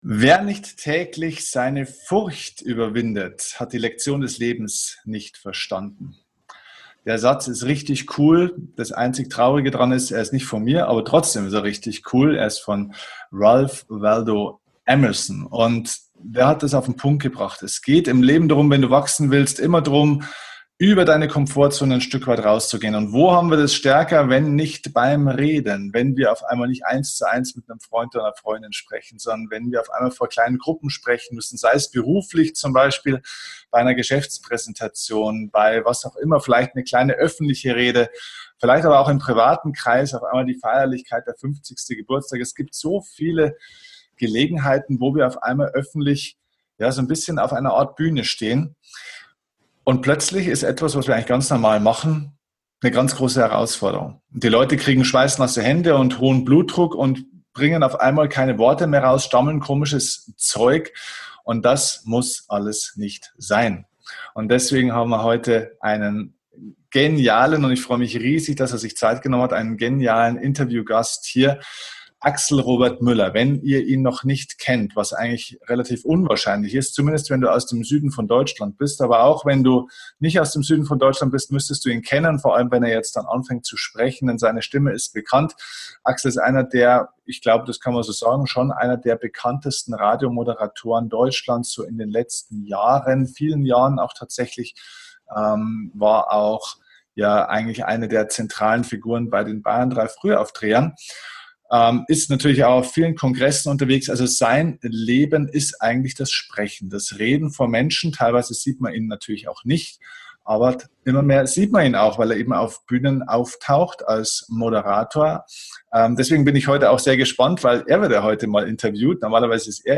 #214 Erfolg Reich Reden - Angstfrei präsentieren wie ein Profi - Interview